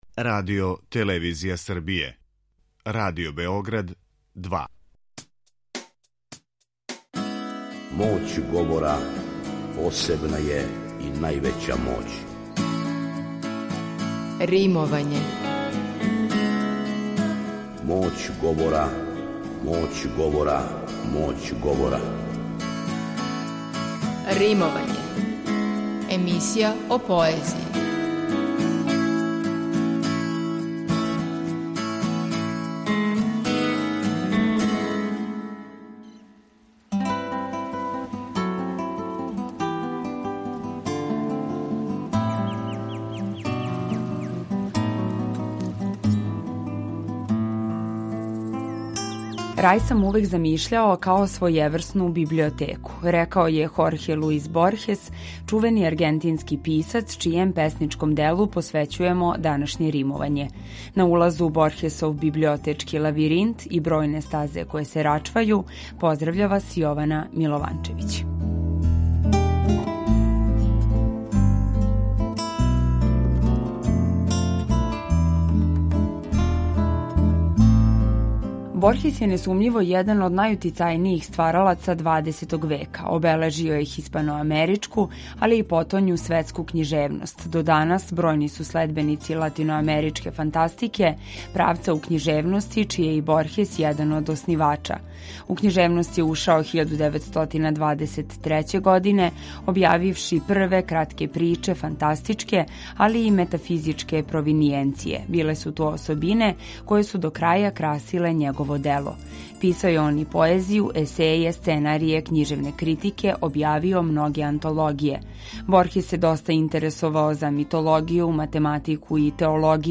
преузми : 17.25 MB Римовање Autor: Група аутора У новој емисији посвећеној поезији, слушаоци ће моћи да чују избор стихова из Звучног архива Радио Београда које говоре најчувенији домаћи и инострани песници, драмски уобличене поетске емисије из некадашње серије „Вртови поезије", као и савремено стваралаштво младих и песника средње генерације.